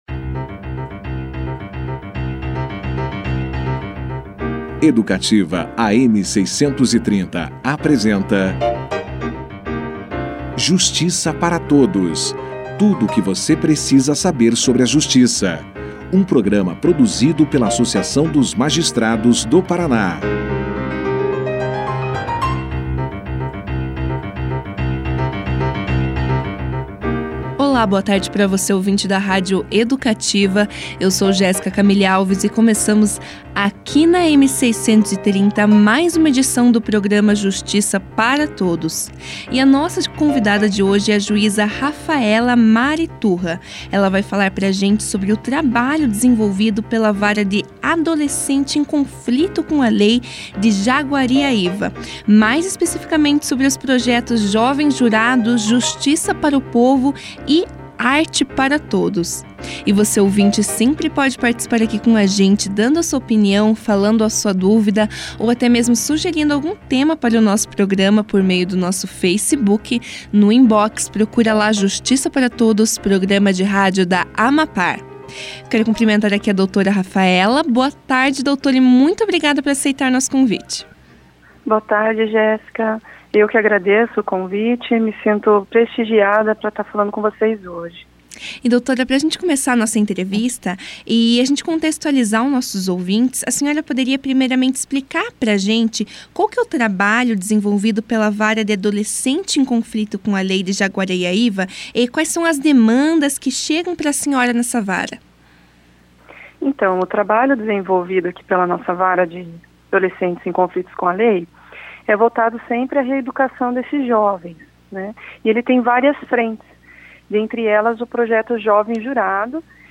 A convidada do programa Justiça para Todos desta terça-feira (6) foi a juíza Rafaela Mari Turra. A magistrada participou do programa da rádio Educativa, AM 630, para contar aos ouvintes sobre o trabalho desenvolvido na Vara de Adolescentes em Conflito com a Lei de Jaguariaíva e os projetos que coordena.